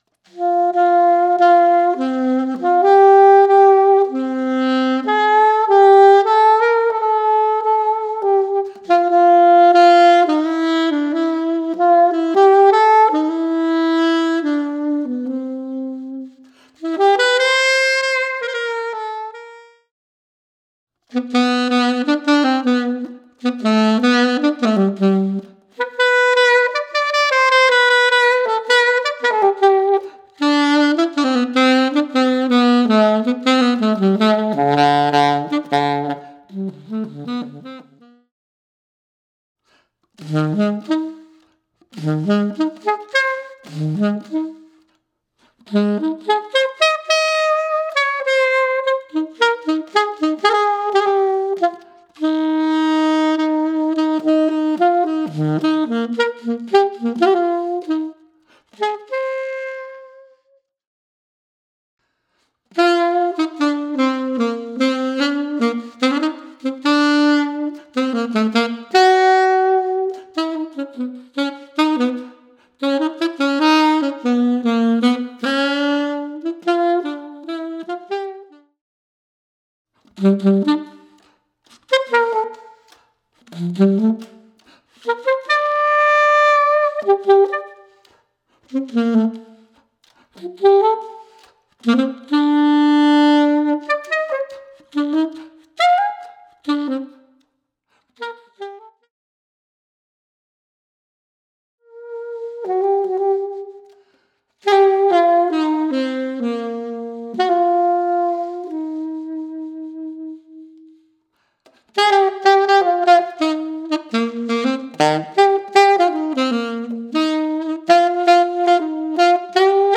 Voicing: Saxophone Alone